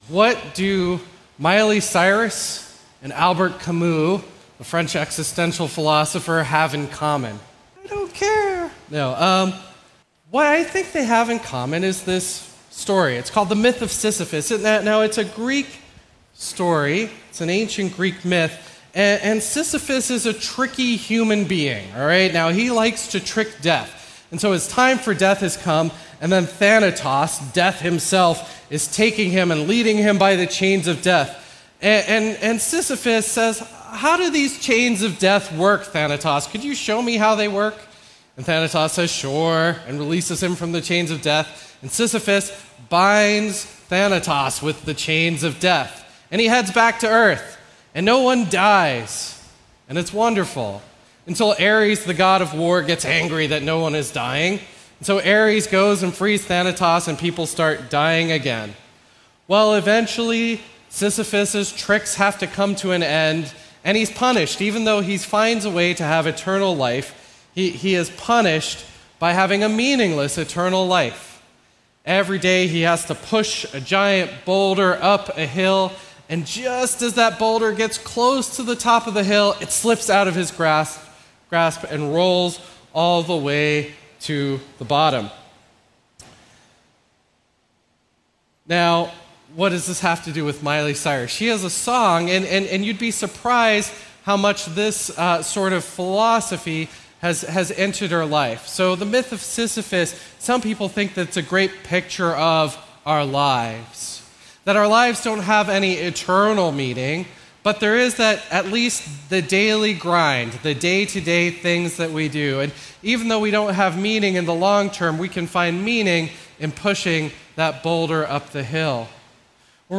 A message from the series "Ecclesiastes."